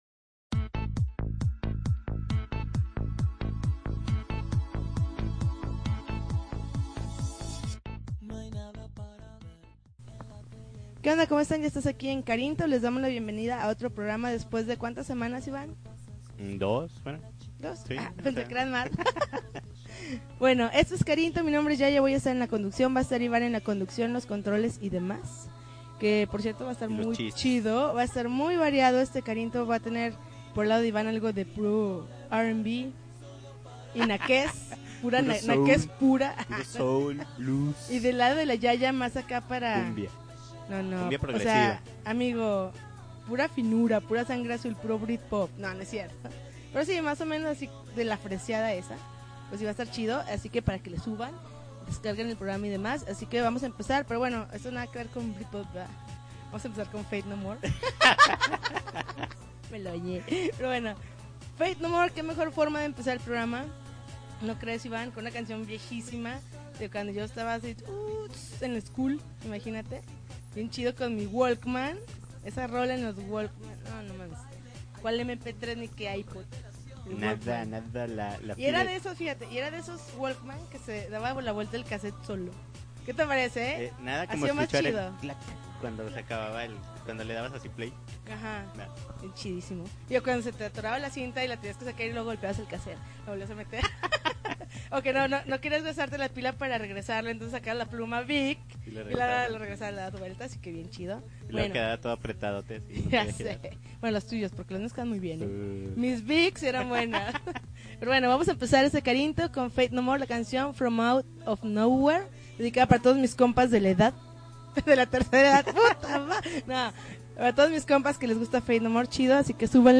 Un nuevo Carinto con publico en vivo, yujuuuuuuu